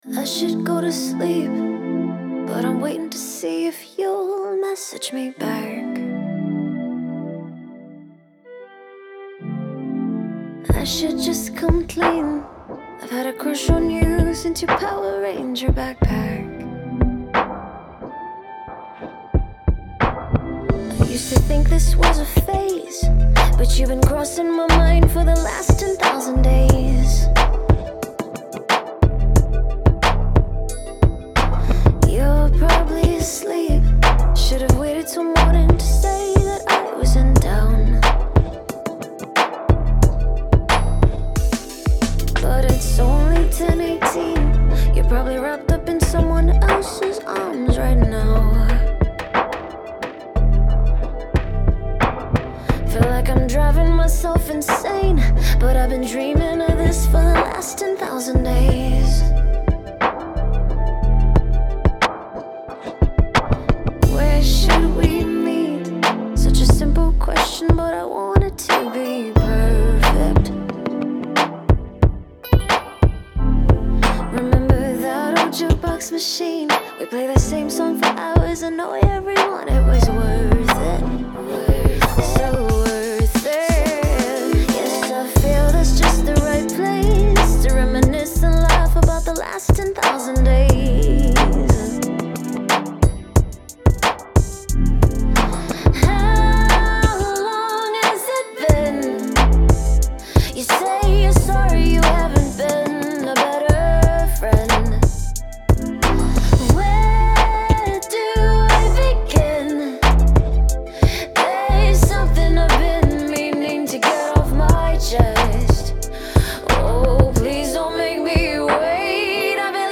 Home > Music > Rnb > Bright > Smooth > Medium